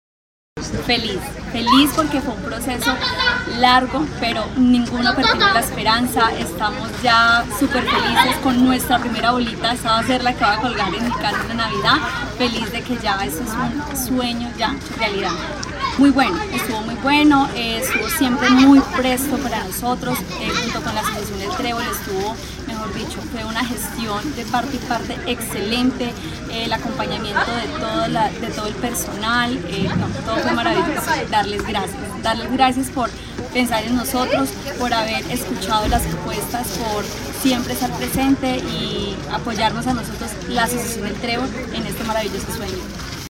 beneficiaria del proyecto.